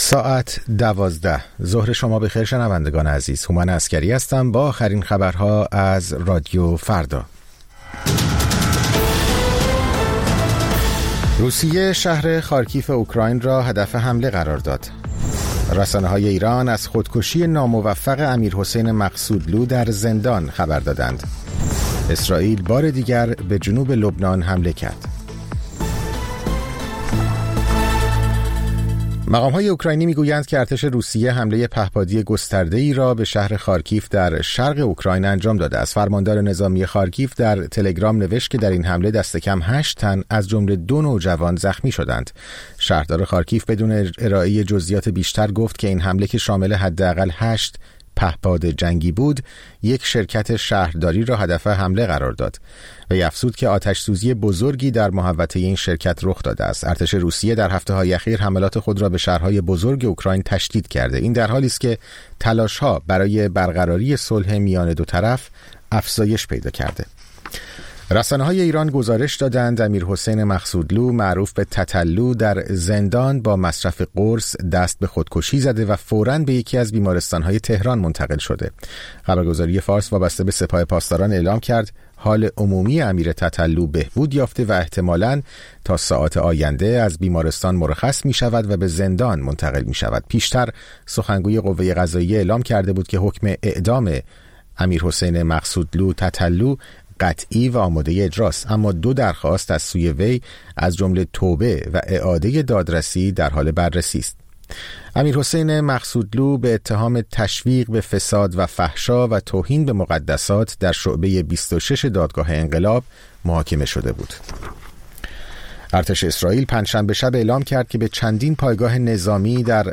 سرخط خبرها ۱۲:۰۰